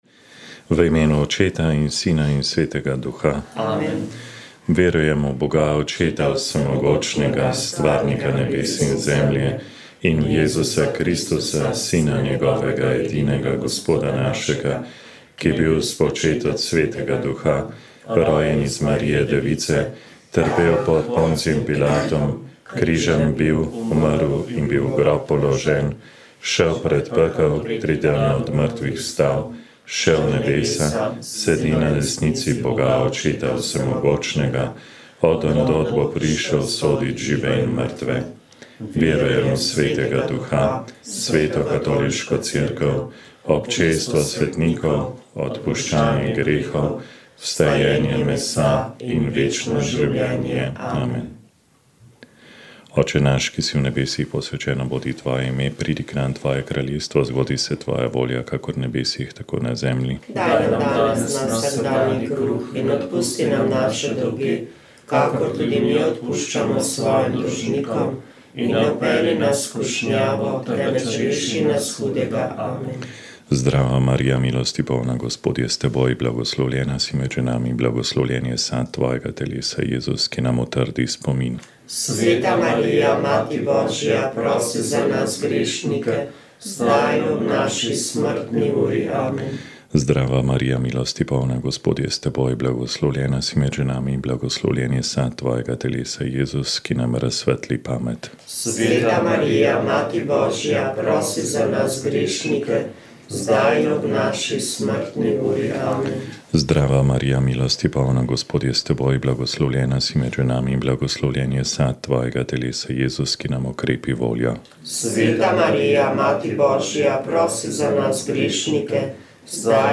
Molile so redovnice - Šolske sestre de Notre Dame.